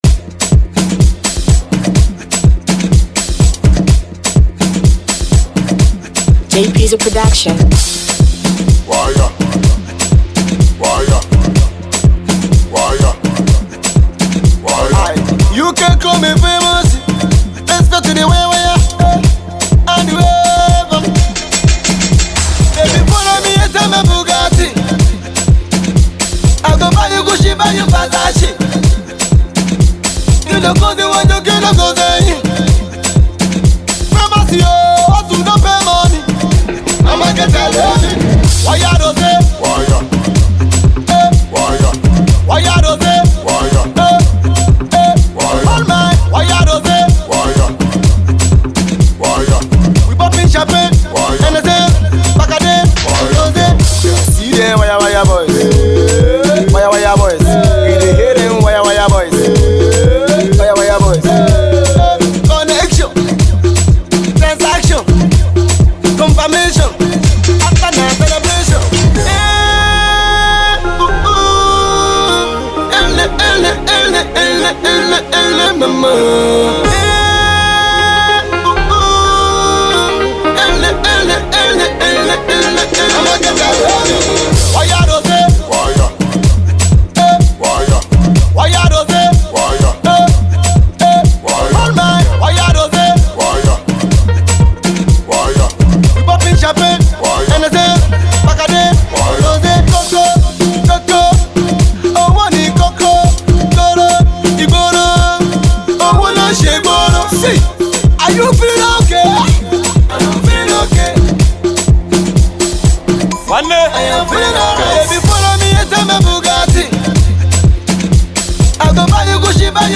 Street Pop